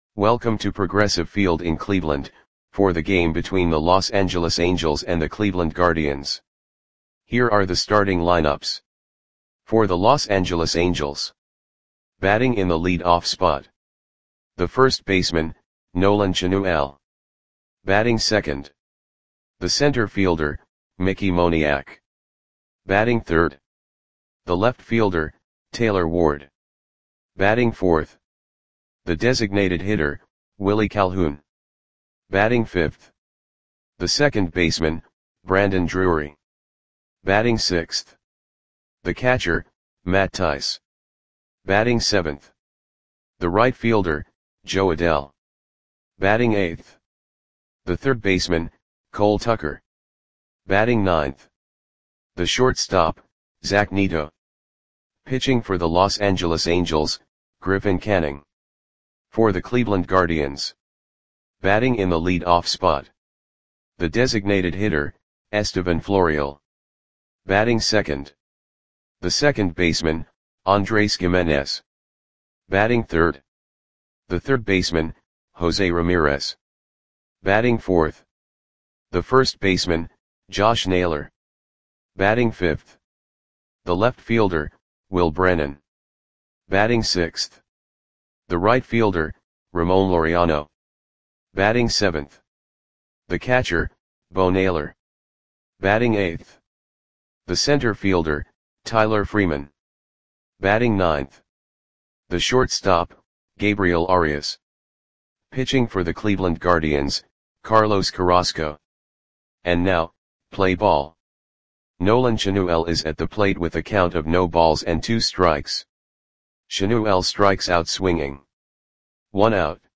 Audio Play-by-Play for Cleveland Indians on May 5, 2024
Click the button below to listen to the audio play-by-play.